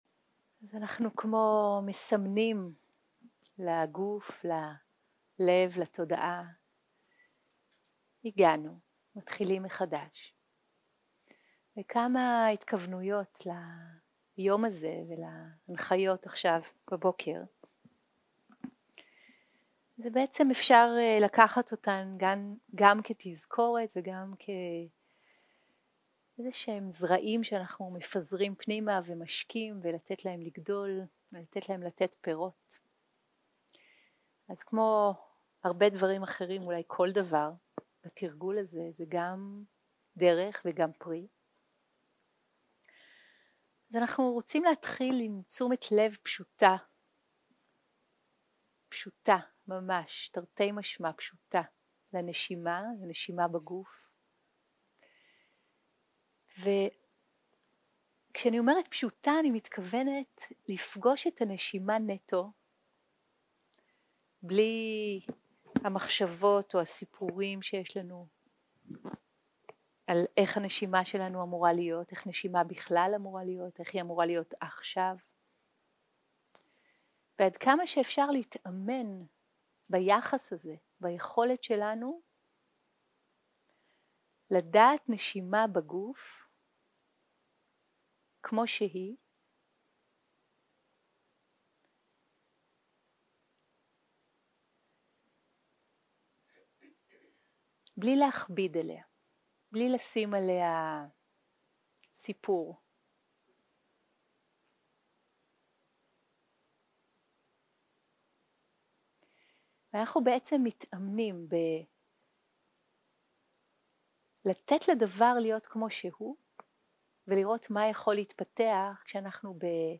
הקלטה 3 - יום 2 - בוקר - הנחיות למדיטציה - תשומת לב לגוף ולנשימה + הנחיות להליכה 0:00 0:00 סוג ההקלטה: Dharma type: Guided meditation שפת ההקלטה: Dharma talk language: Hebrew